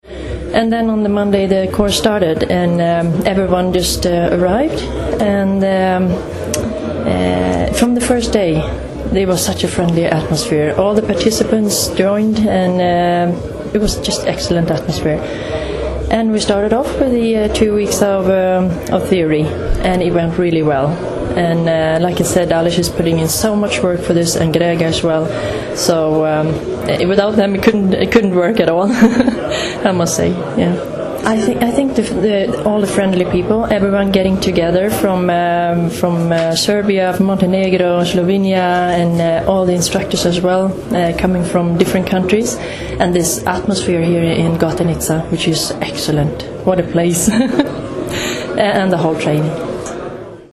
statement